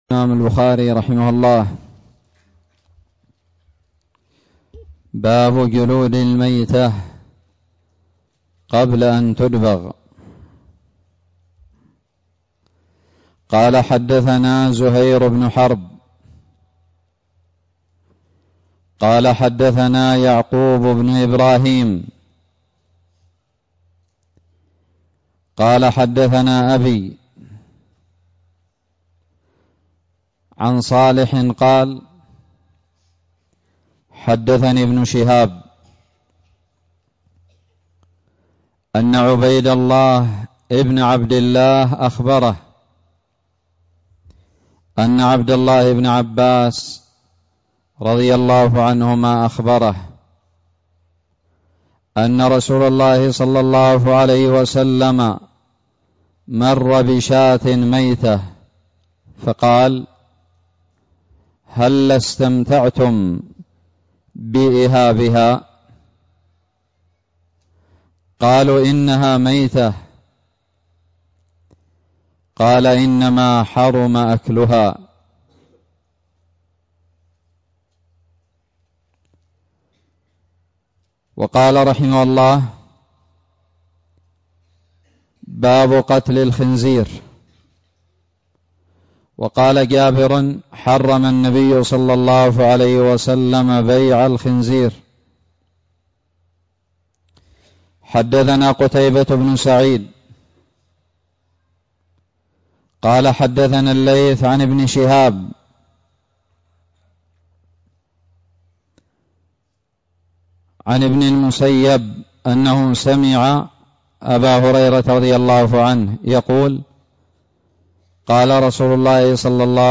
الدروس
ألقيت بدار الحديث السلفية للعلوم الشرعية بالضالع